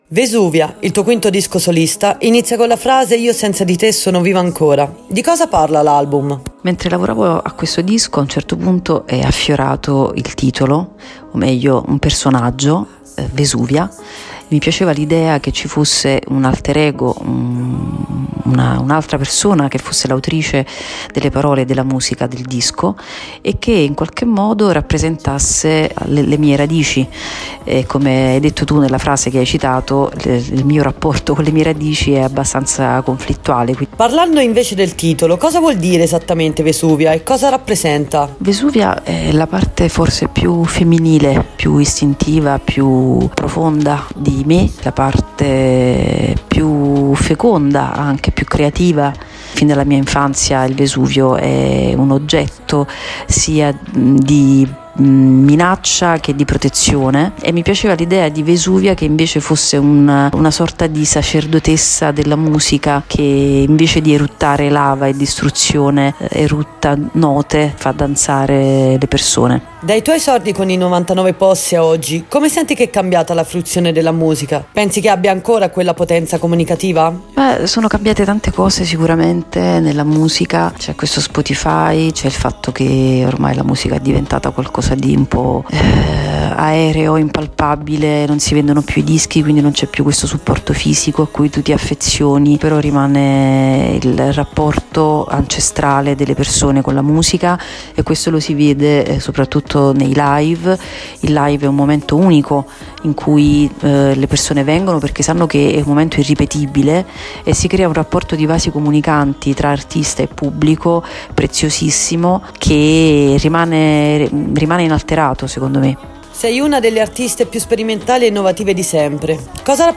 Meg, una delle autrici più eclettiche e sperimentali del panorama italiano in esclusiva ai nostri microfoni.
Intervista-meg-con-base.mp3